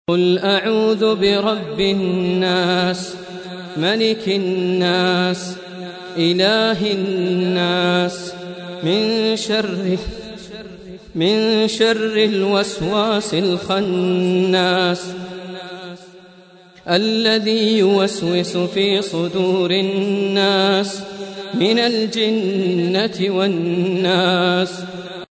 Surah Sequence تتابع السورة Download Surah حمّل السورة Reciting Murattalah Audio for 114. Surah An-N�s سورة النّاس N.B *Surah Includes Al-Basmalah Reciters Sequents تتابع التلاوات Reciters Repeats تكرار التلاوات